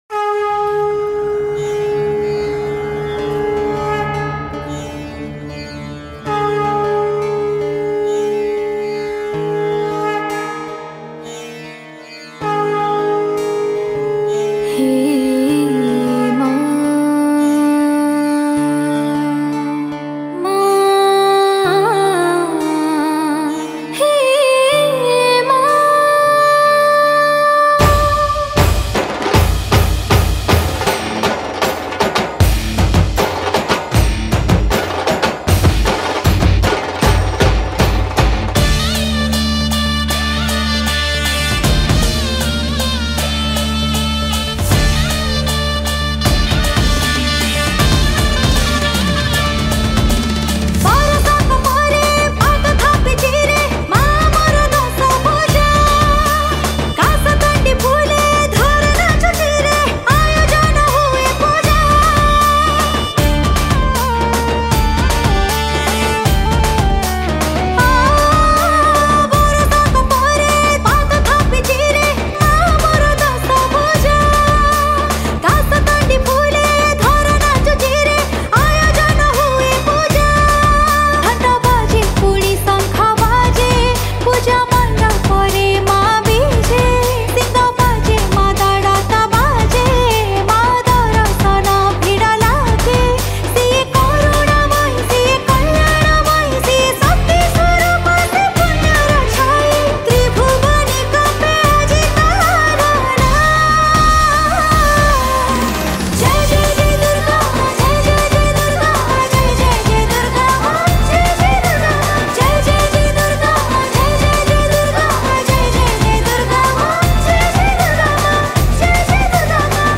Durga Puja Special Song 2022 Songs Download